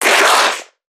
NPC_Creatures_Vocalisations_Infected [27].wav